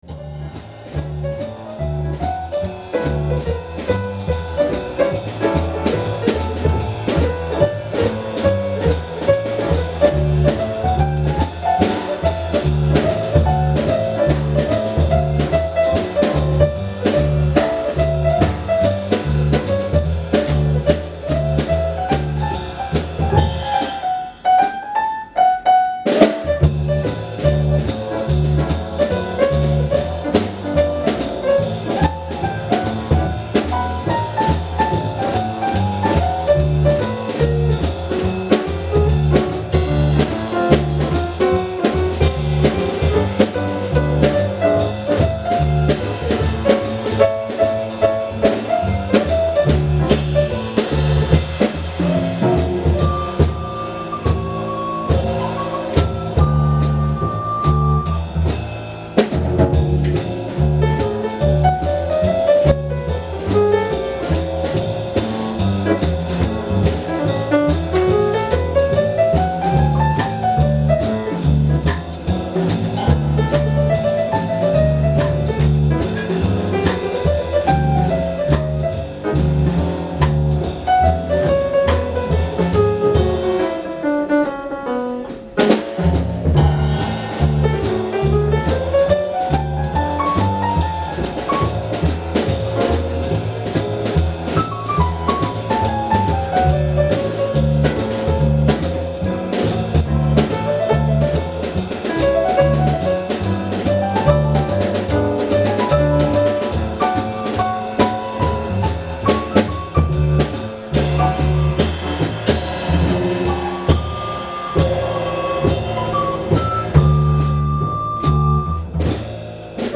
2004年の発表会、全員参加で「L-O-V-E」をメドレーで弾きました。
演奏してもらいました。それぞれの個性を味わって頂けるでしょうか？